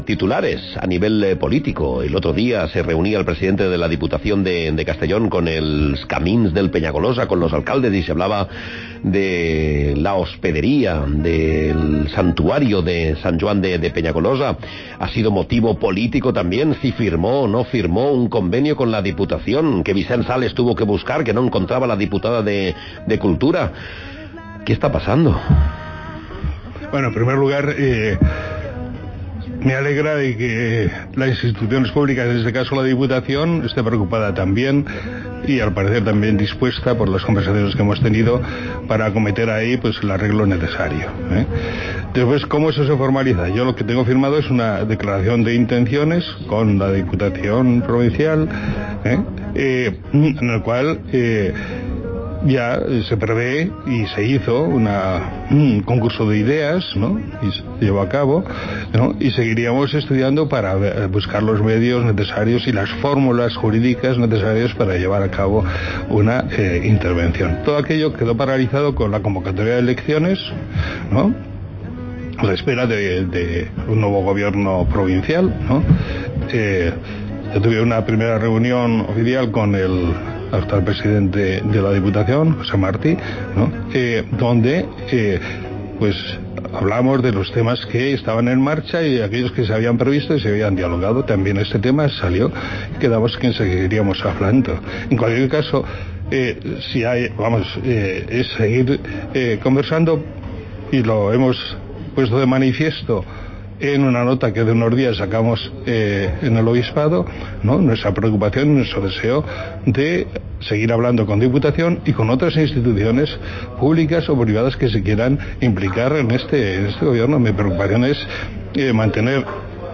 El obispo de Segorbe-Castellón se pronuncia sobre la situación de Sant Joan de Penyagolosa
Sobre el santuario de Sant Joan de Penyagolosa se ha pronunciado en COPE el obispo de la diócesis de Segorbe-Castellón, monseñor Casimiro López Llorente, quien ha señalado que si hay firmada una declaración de intenciones para la cesión del ermitorio, el restaurante y la hospedería a la Diputación para su rehabilitación y reapertura.